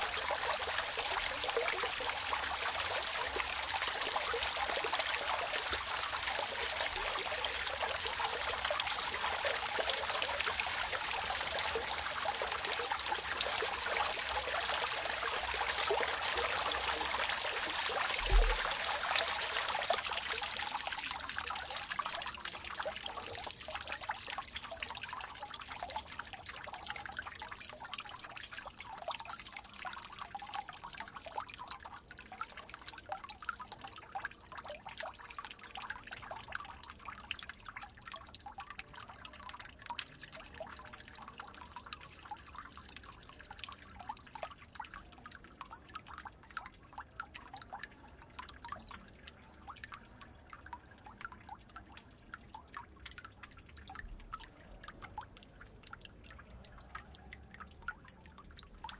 喷泉，被关闭
描述：一个喷泉正在夜间关闭，夏天在城市
Tag: 背景音 气氛 环境 背景 ATMOS 气氛 气氛 ATMO 现场记录 立体声